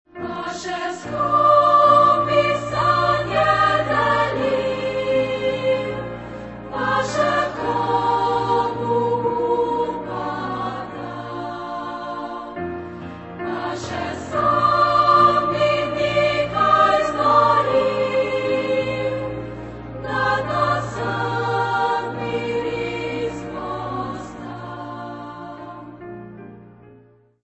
SA (2 voices children OR women) ; Full score.
Mood of the piece: gentle Type of Choir: SA (2 children OR women voices ) Instrumentation: Piano (1 instrumental part(s))
Tonality: B flat major